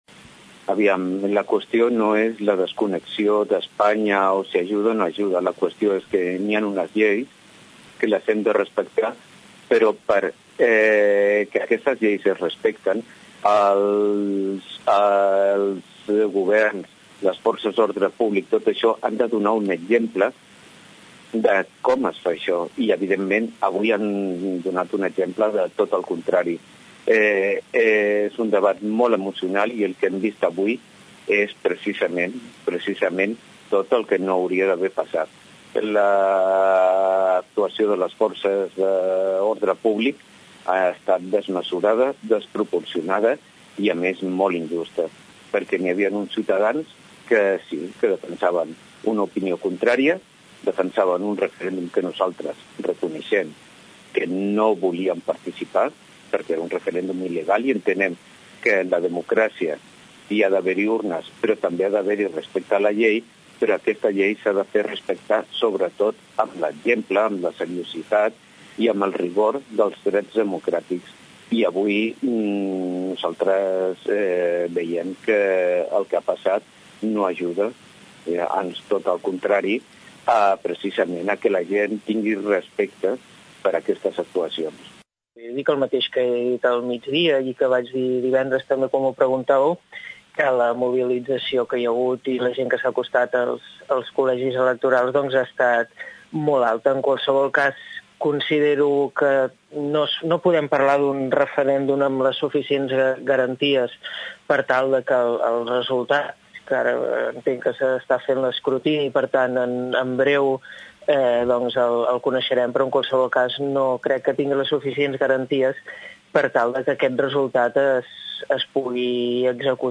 Consultats per aquesta emissora, els representants polítics locals també van valorar la jornada. Tots van coincidir amb la desproporció de l’actuació policial i la  incertesa del moment.